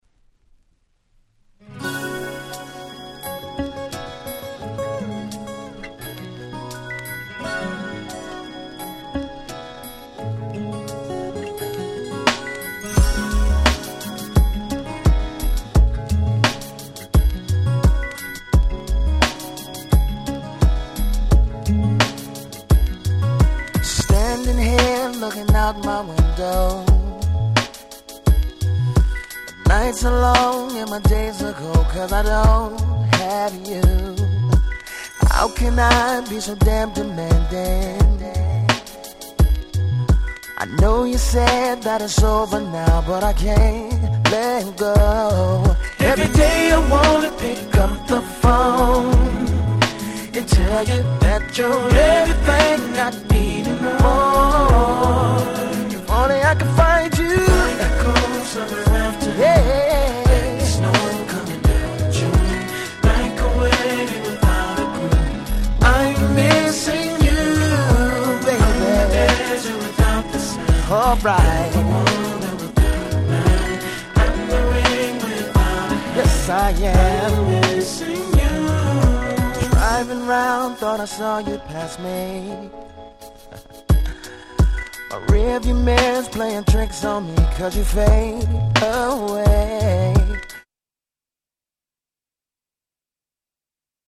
00' Smash Hit R&B♪
この辺の美メロSmooth男性Vocalモノが好きなら間違いなくドンピシャでしょ！！